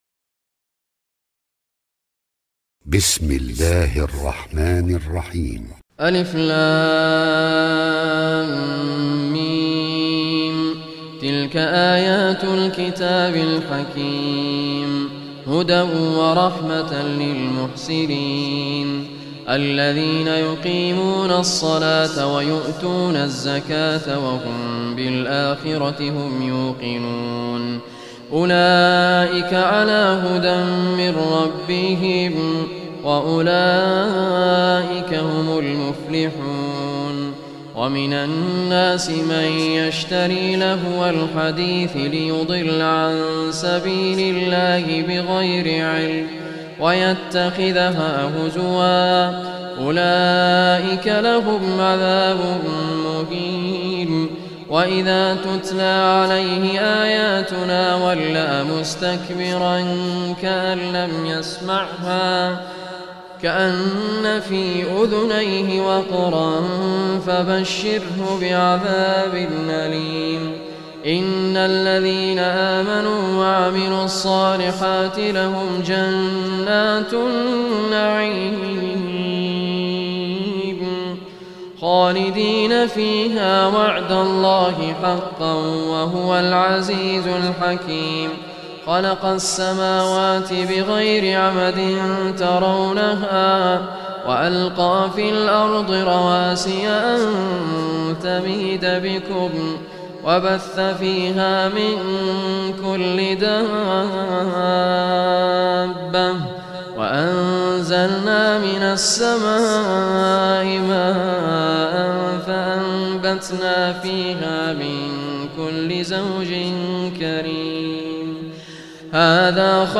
Surah Luqman MP3 Recitation by Sheikh Raad Kurdi
Surah Luqman, listen or play online mp3 tilawat / recitation in the beautiful voice of Sheikh Raad Al Kurdi. Surah Luqman is 31st chapter of Holy Quran.